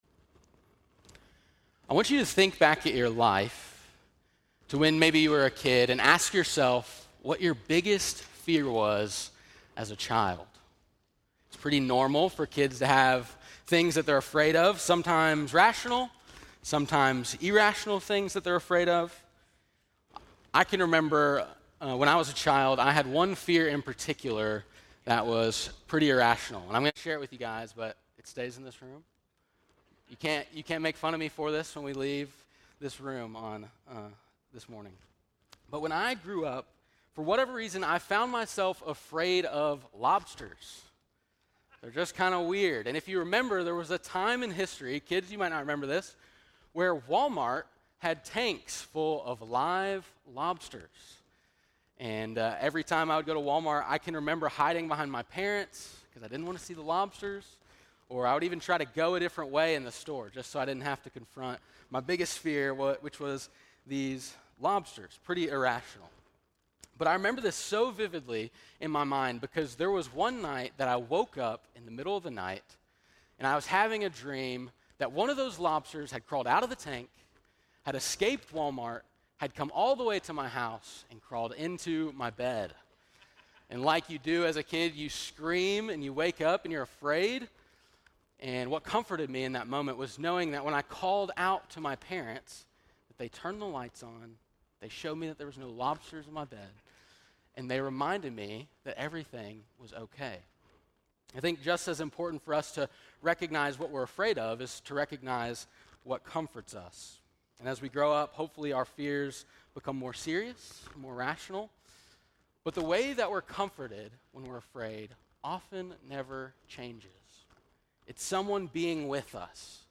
10.27-sermon.mp3